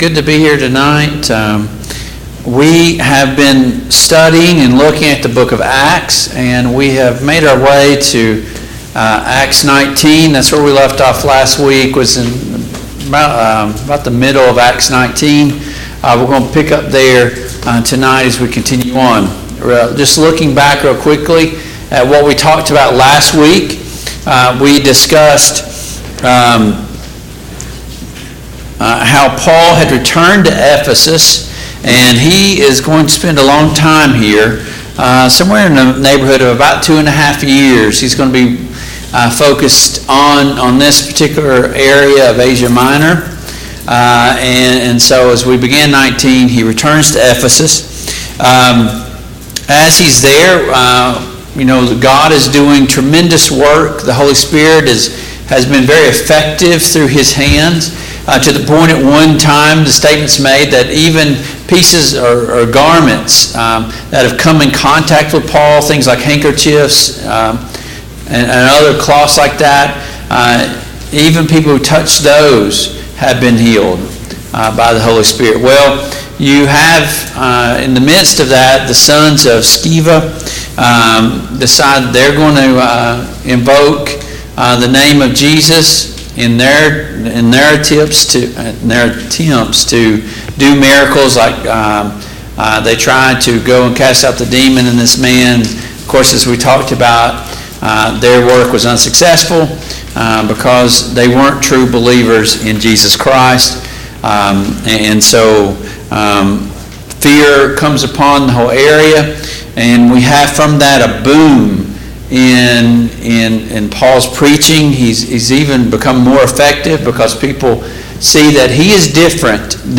Passage: Acts 19:21-41; Acts 20:1-6 Service Type: Mid-Week Bible Study